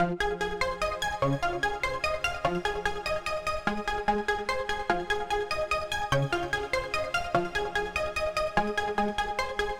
• Phrenetic background synth 196 - A flat major.wav